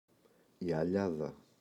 αλιάδα, η [a’ʎaða]